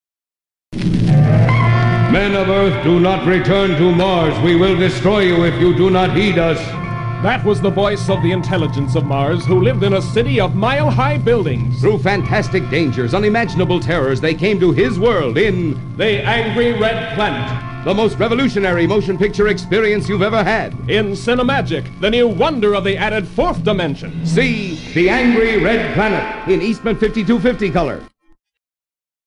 The Angry Red Planet (1959) Radio Spots
I was somewhat surprised to see that American International’s publicity department released only two radio spots for it – a sixty and a thirty.